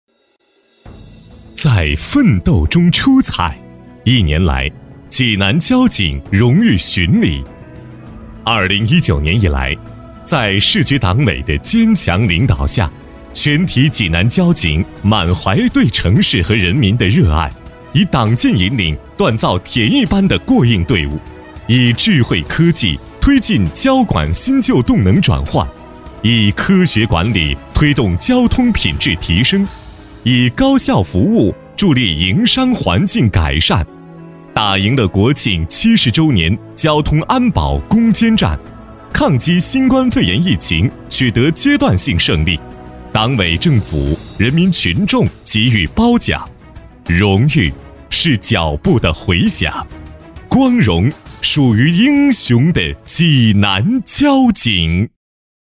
男66
专业配音老师